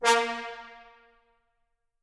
Fat_Horn_4.wav